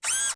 fs_mechanical_sm.wav